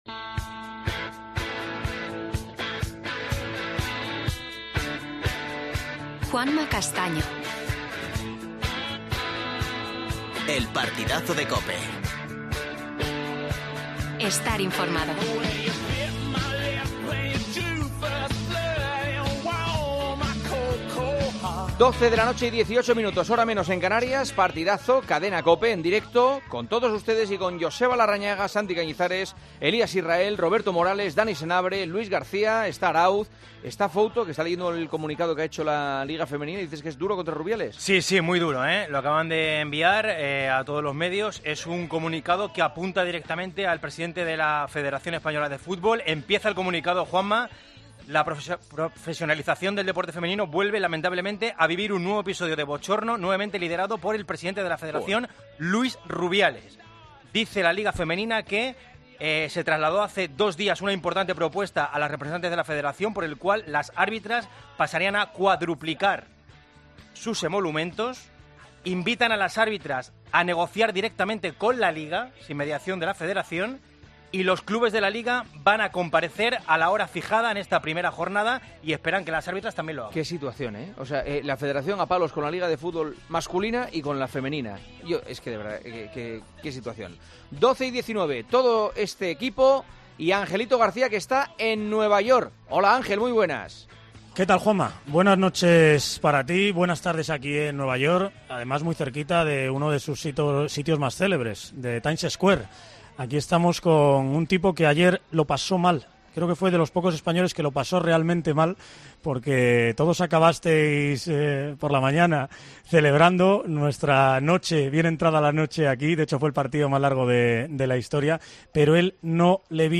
Carlos Alcaraz se mete en semifinales del US Open tras una espectacular partido. Entrevista a Ferrero.